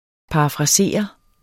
Udtale [ pɑɑfʁɑˈseˀʌ ]